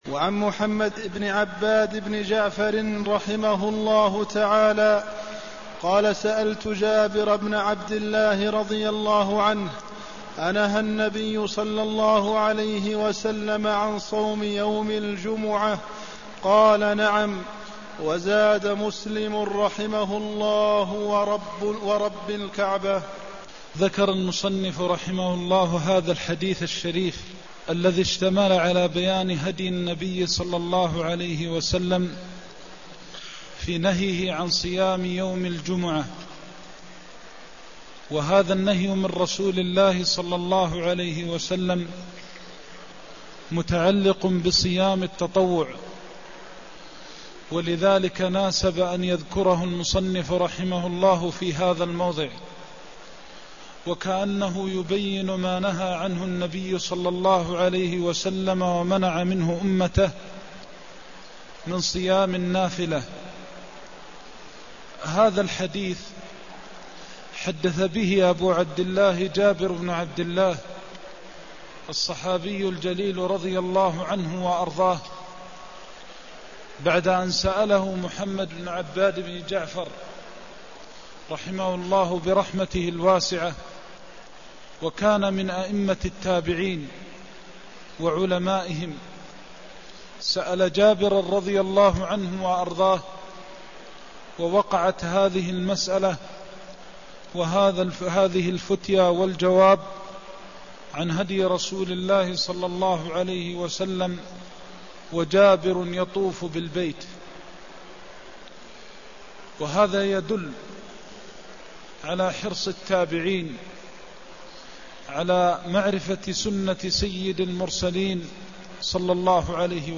المكان: المسجد النبوي الشيخ: فضيلة الشيخ د. محمد بن محمد المختار فضيلة الشيخ د. محمد بن محمد المختار كراهة صوم يوم الجمعة وحده (191)